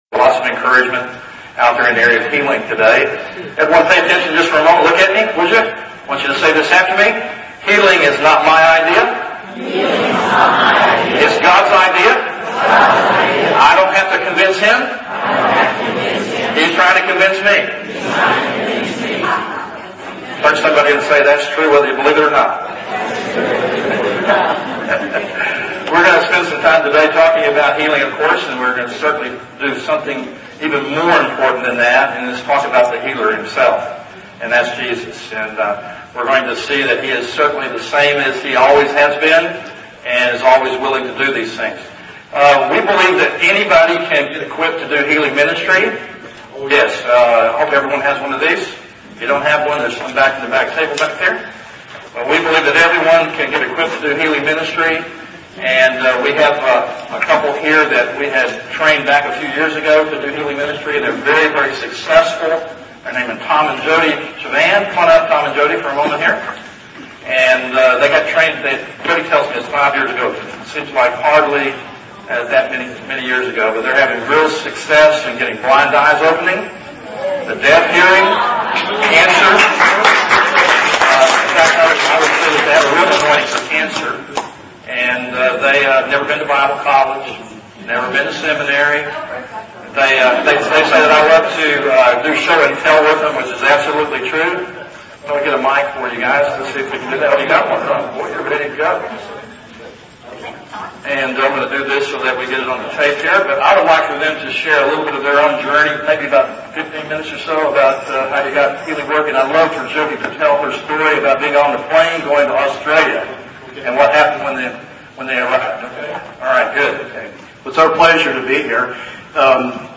Anatole Hotel, Dallas, TX